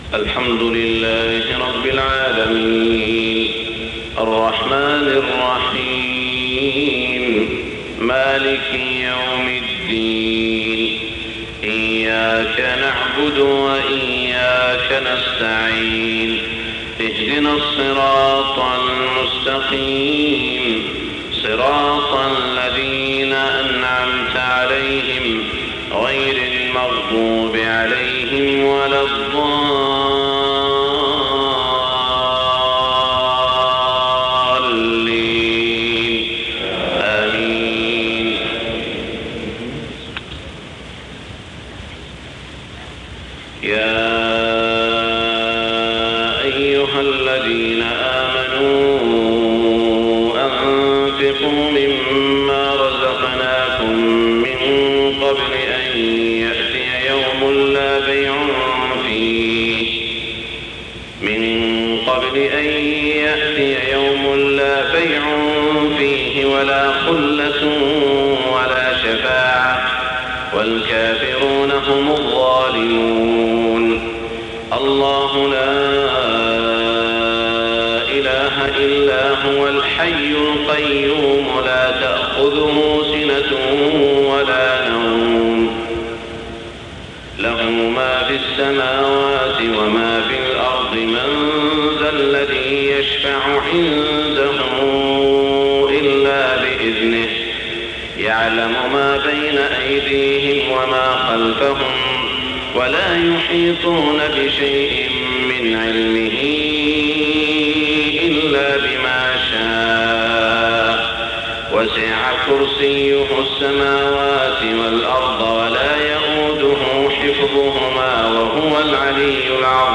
صلاة الفجر -2-1426 من سورة البقرة > 1426 🕋 > الفروض - تلاوات الحرمين